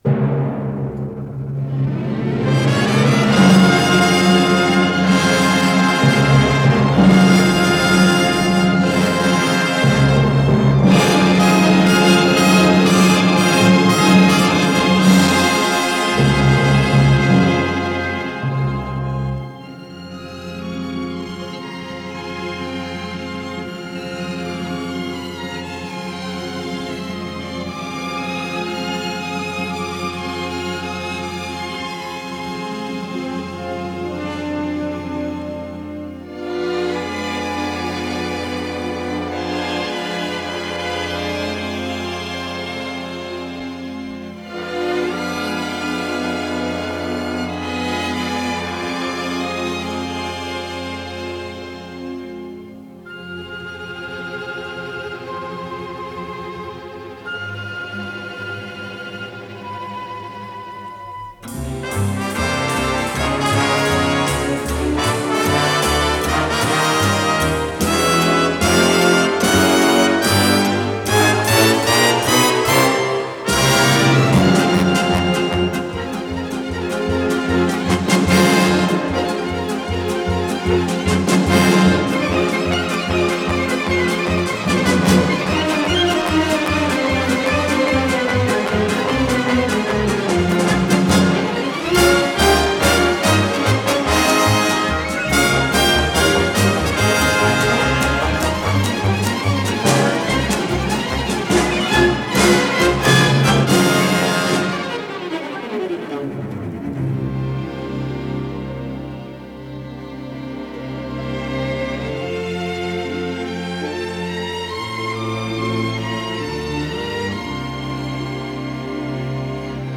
ВариантДубль моно